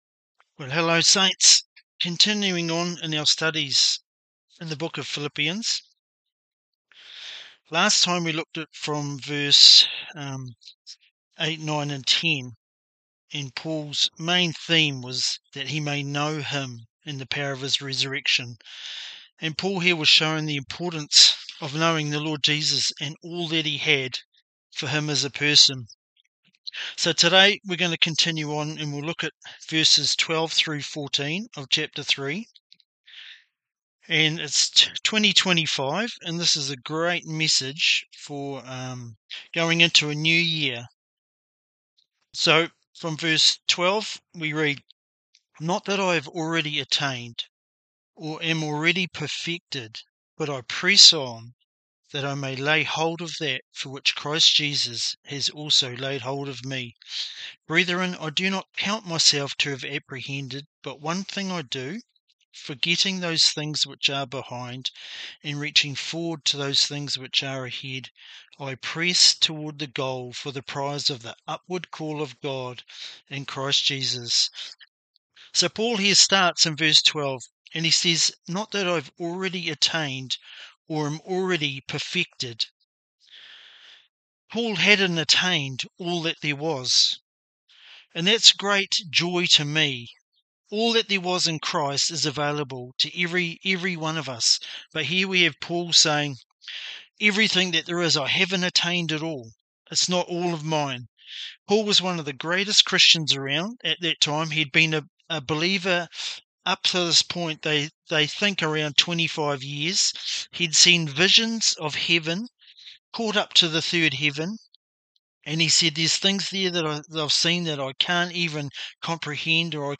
Philippians 3:12-14 Bible lesson: We are called to forget what's behind, reach forward to what's ahead, and press on toward the goal for the prize of the upward call of God in Christ Jesus